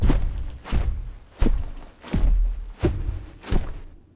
HighGroundRoyaleNetcode / Assets / Sounds / Character / Boss / walk_a.wav
walk_a.wav